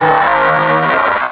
Cri de Brouhabam dans Pokémon Rubis et Saphir.